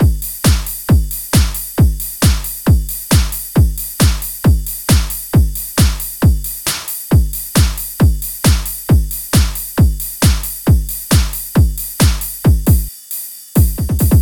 Session 04 - Mixed Beat 01.wav